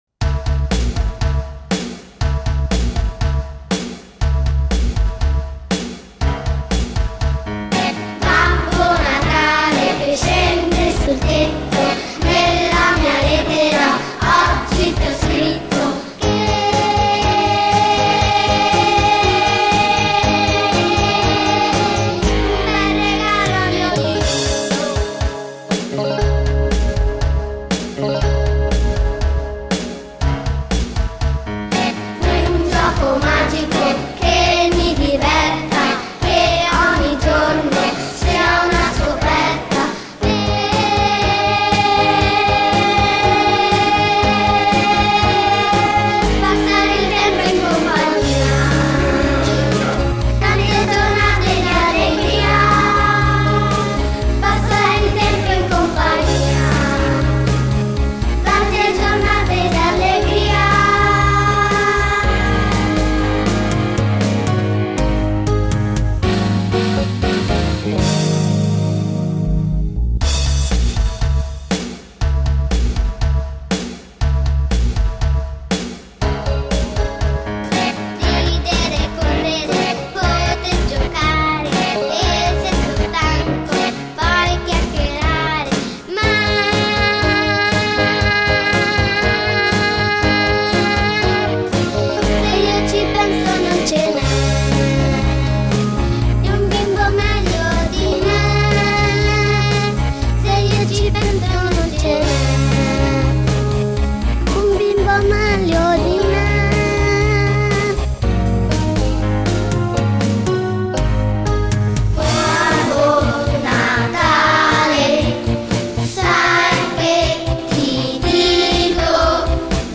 Cantato